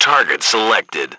1 channel
H_soldier1_20.wav